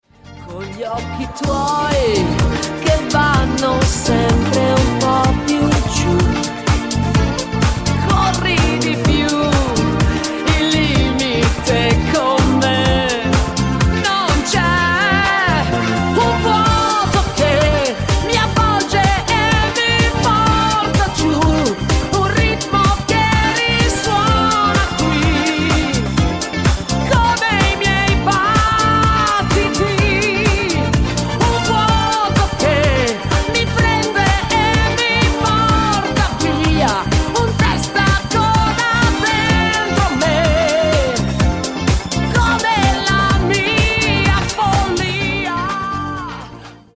DISCO  (3.54)